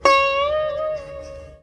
SITAR LINE65.wav